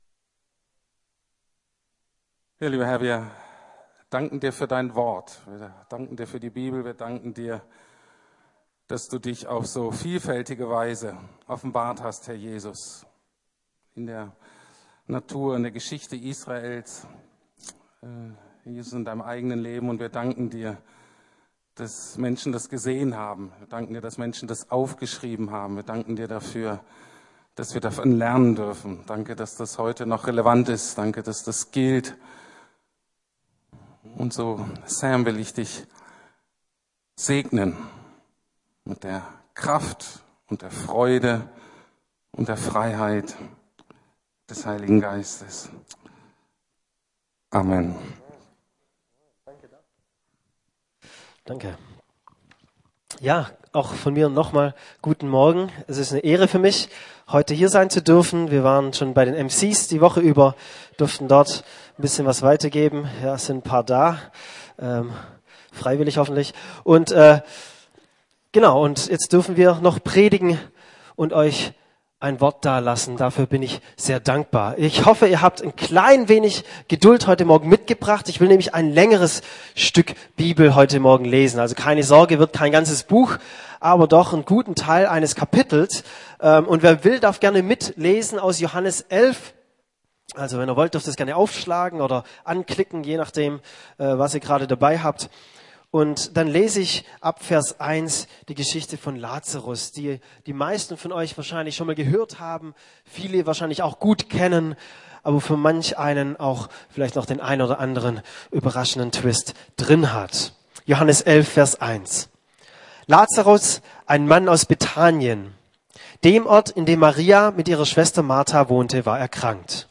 Jesus, König meines Herzen. ~ Predigten der LUKAS GEMEINDE Podcast